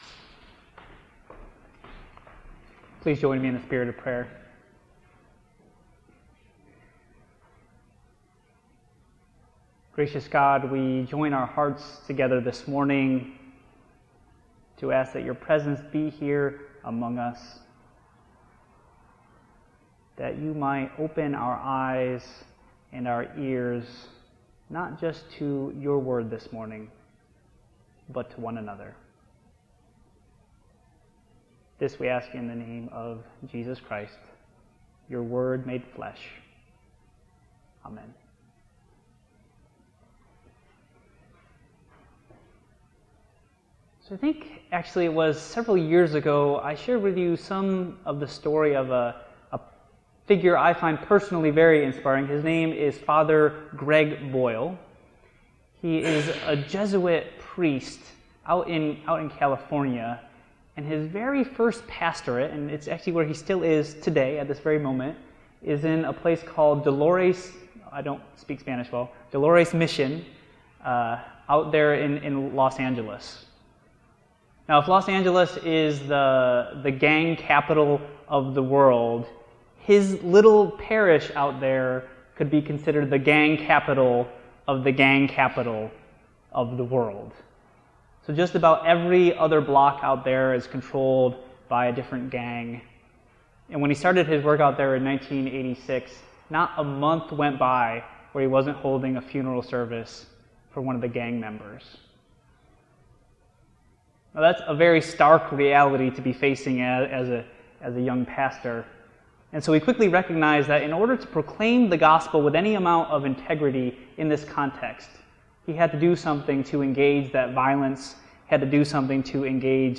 The doxology is a favorite hymn sung by Christians throughout the world.
pianist